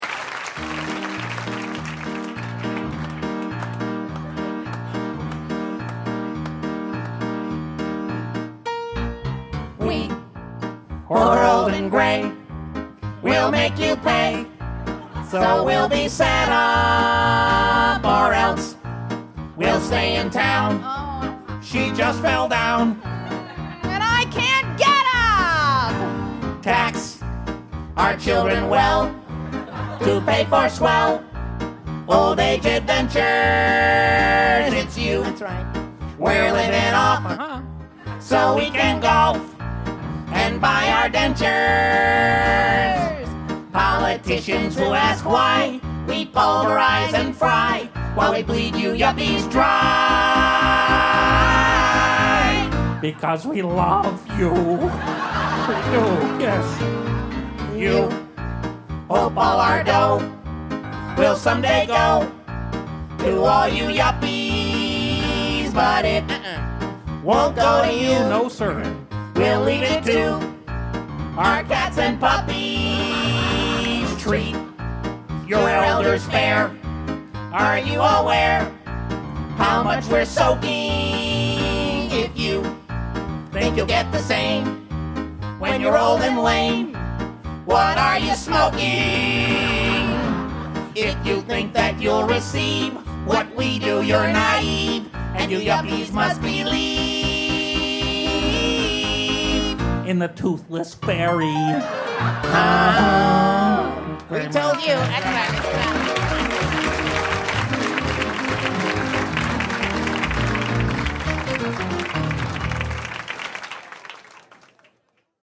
political parody songs